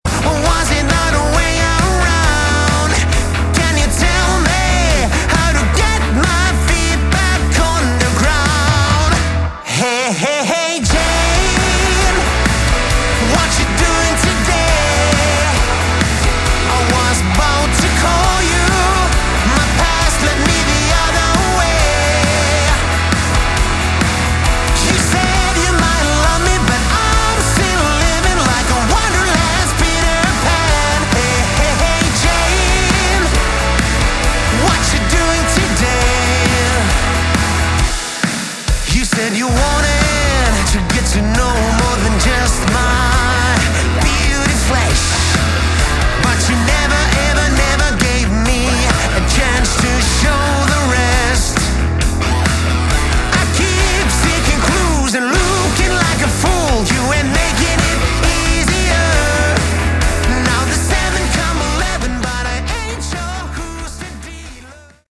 Category: Melodic Rock
vocals
guitars
drums
bass